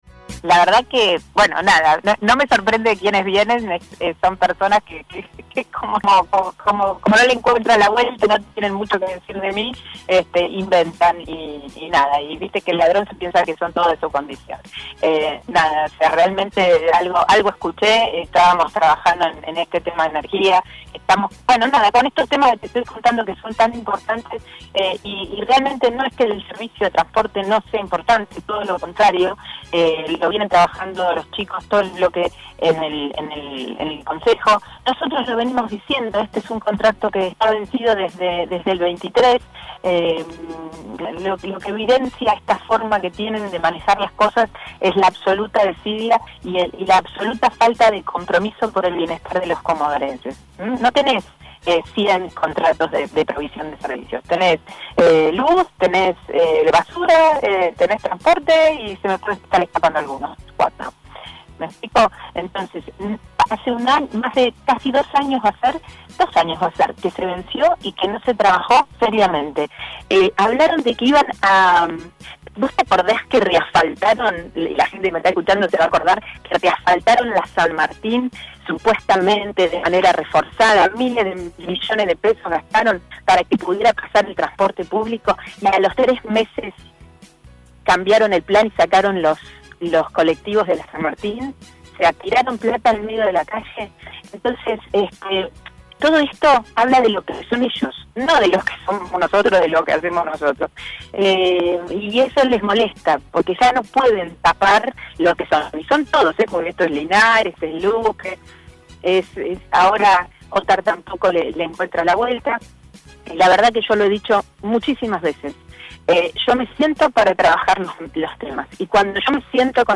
La Diputada Nacional respondió a todo